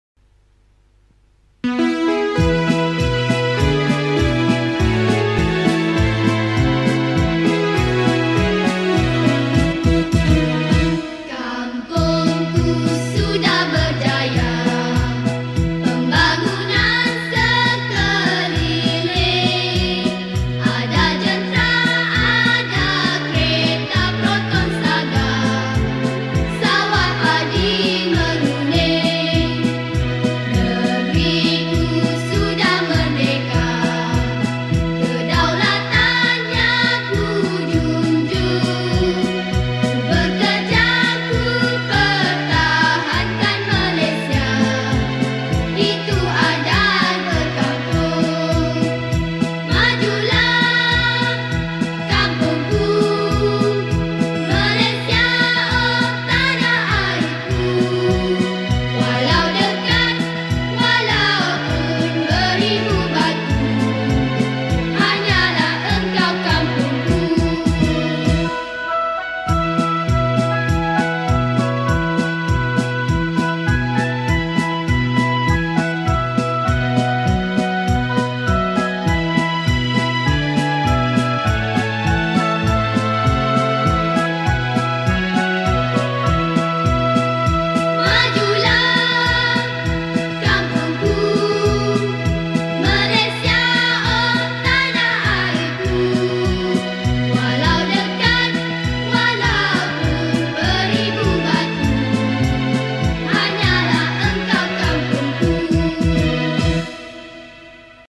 Adapted from popular Minang Song
Patriotic Songs
Skor Angklung